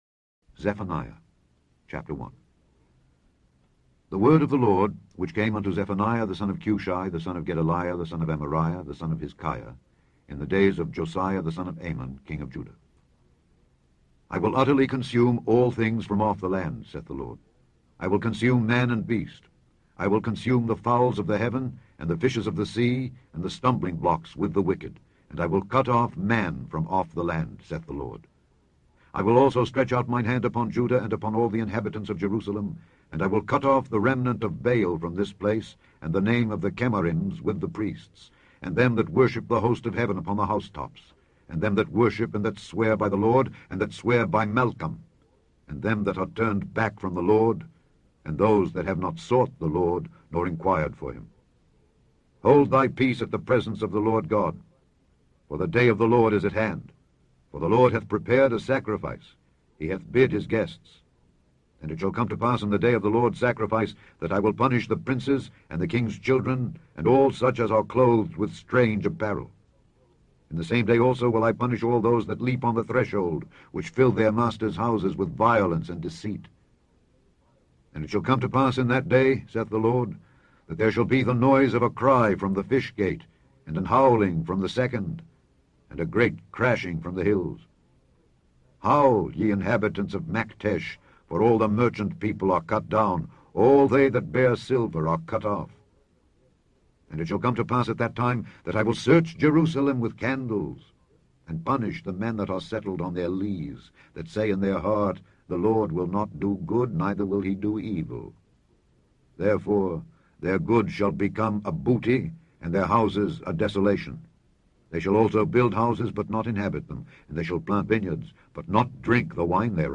Scourby Audio Bible